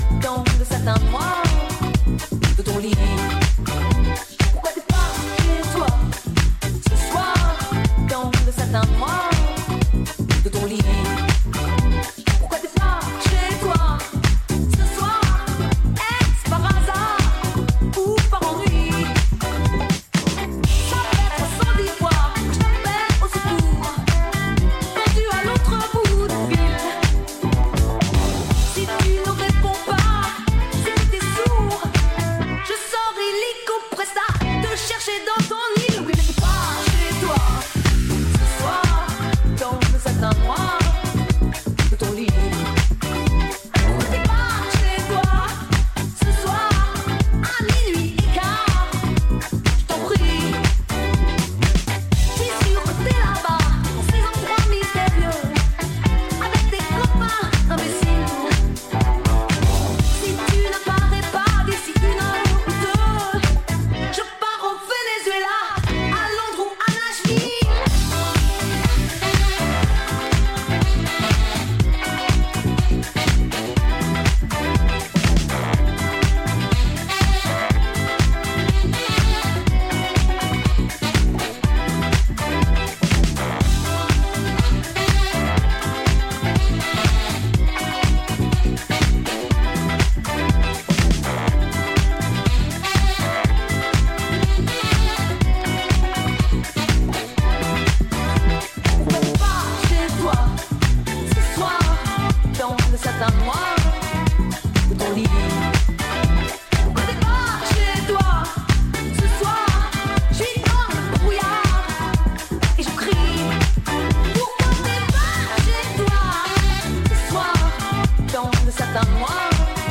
a go-go-soaked funk jam with razor-sharp percussion